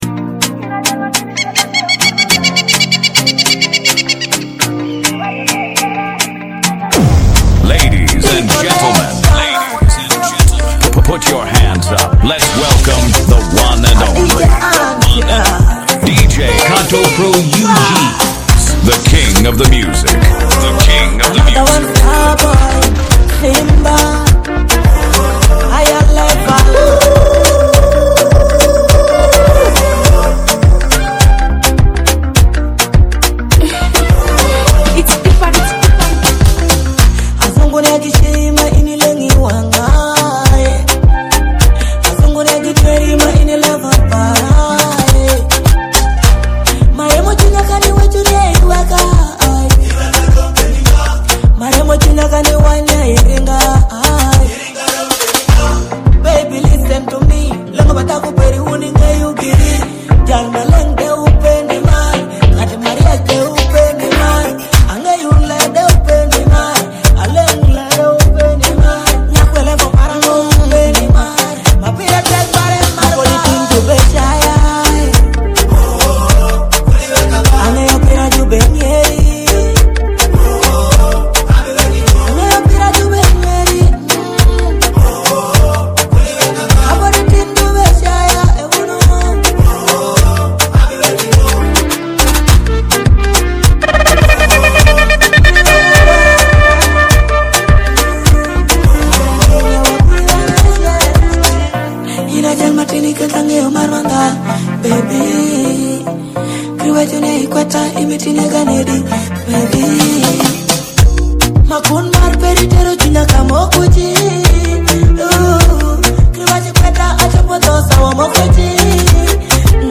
Latest Alur Music